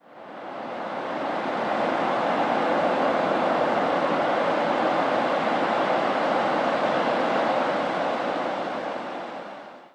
描述：海滩，大海，海洋
Tag: 海浪 海洋